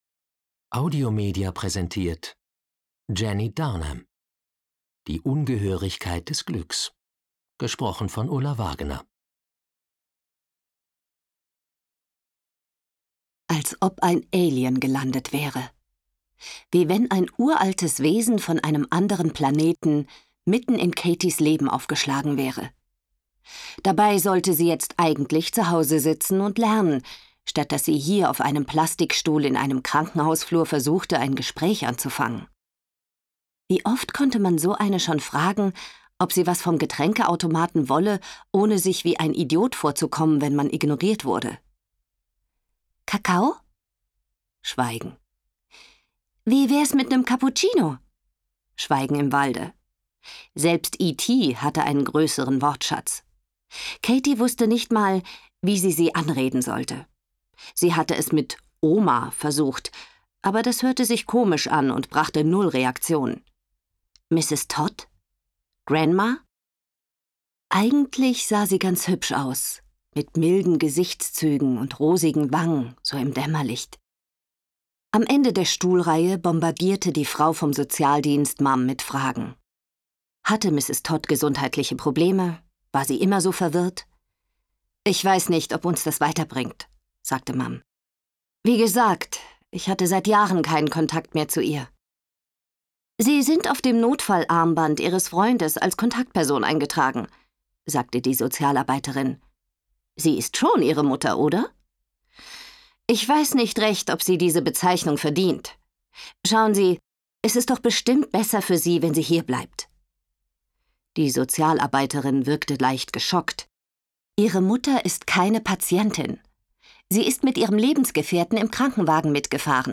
Hörbuch; Literaturlesung